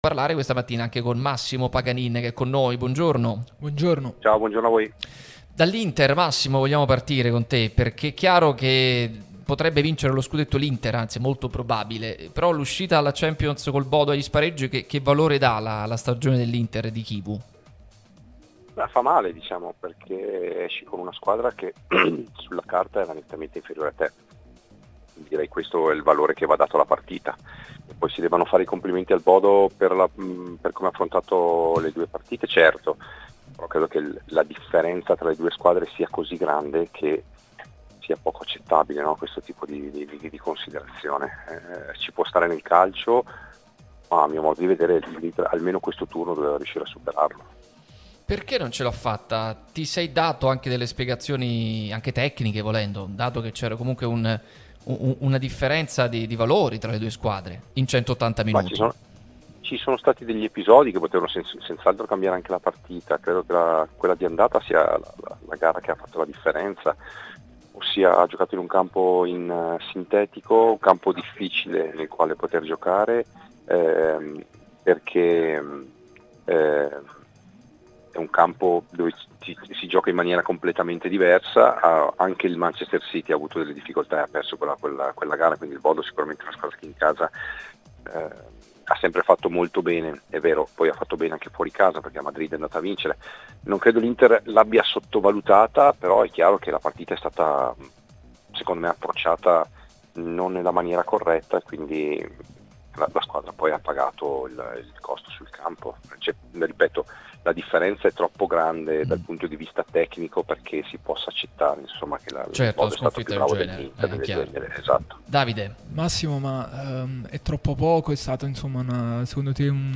L'ex Inter Massimo Paganin è intervenuto durante Pausa Caffè su Radio Tutto Napoli, prima radio tematica sul Napoli, che puoi seguire sulle app gratuite (scarica qui per Iphone o per Android), qui sul sito anche in video.